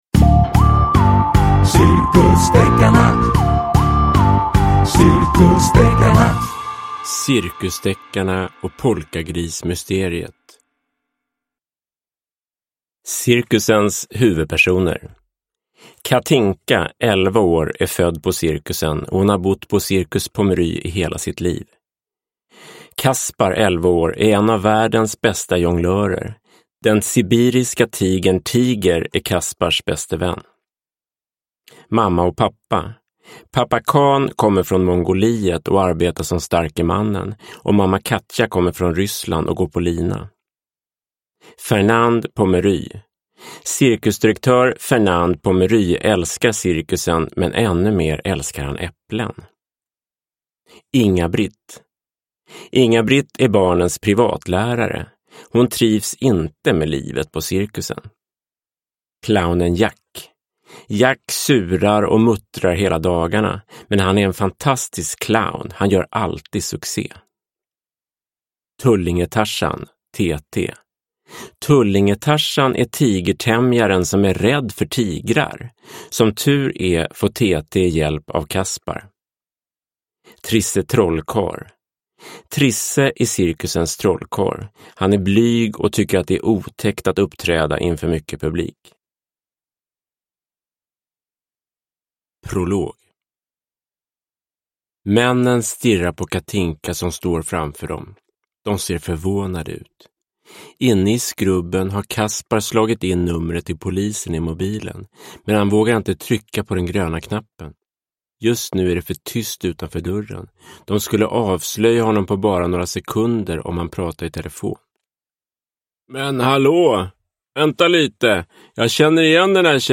Cirkusdeckarna och polkagrismysteriet – Ljudbok – Laddas ner